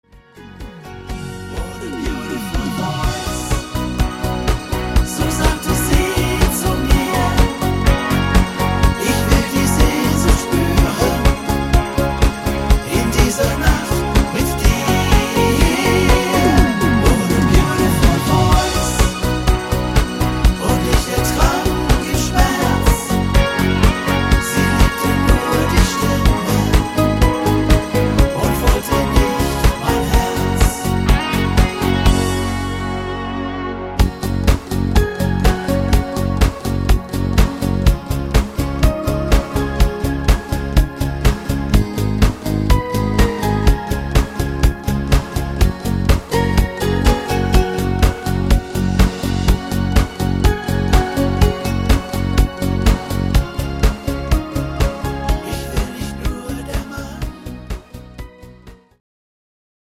deutscher Schlager